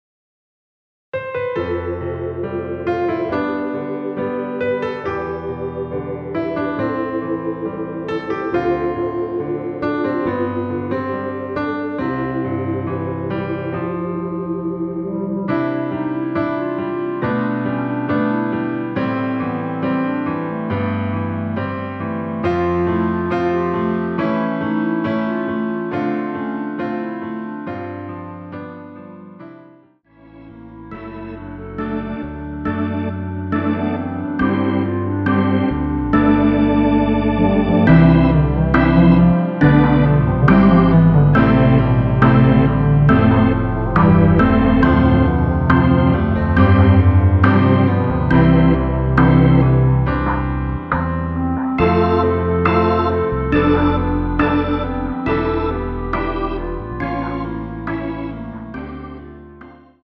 중간 간주 부분이 길어서 짧게 편곡 하였습니다.(미리듣기 확인)
원키에서(-2)내린 멜로디 포함된 간주 짧게 편곡한 MR입니다.
앞부분30초, 뒷부분30초씩 편집해서 올려 드리고 있습니다.
중간에 음이 끈어지고 다시 나오는 이유는